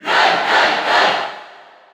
Category: Crowd cheers (SSBU) You cannot overwrite this file.
Roy_Cheer_Japanese_SSBU.ogg